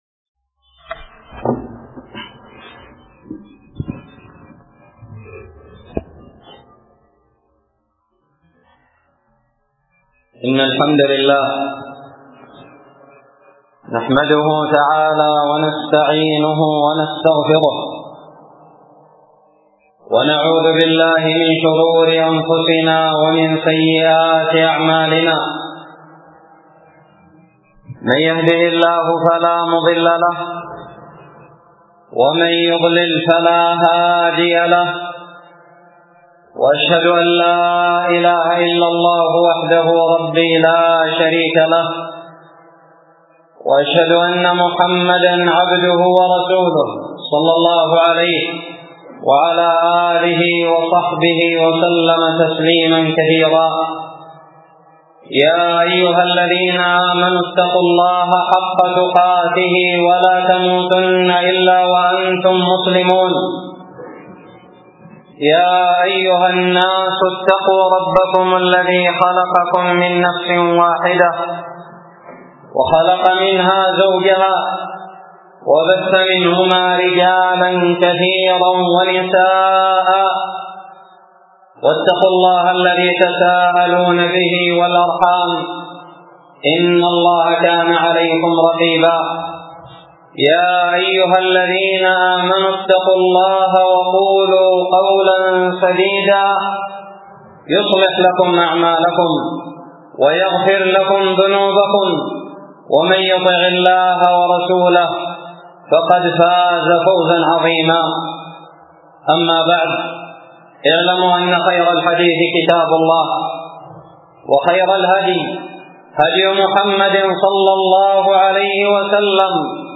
خطب الجمعة
ألقيت بدار الحديث السلفية للعلوم الشرعية بالضالع في 17 شوال 1437هــ